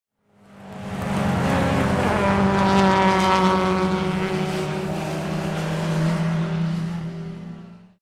Race Cars Passing On Track Sound Effect
Lower-class cars speeding up and passing on a race track. Ideal high-speed racing atmosphere for films, video games, YouTube videos, multimedia projects, and automotive content.
Race-cars-passing-on-track-sound-effect.mp3